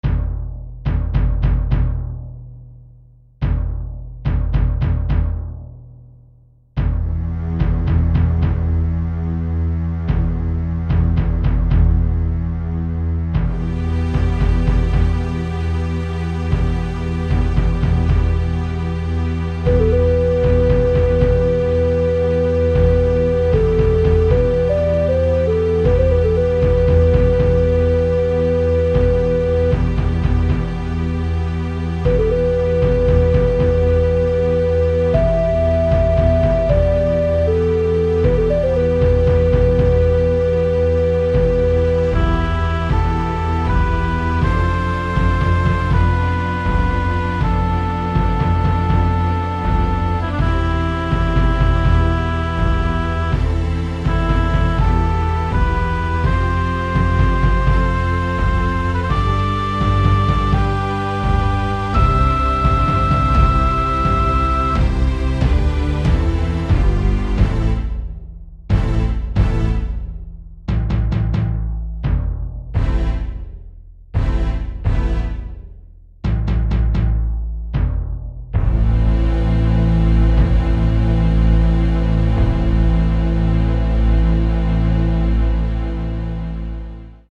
Soundtrack
Musical Score: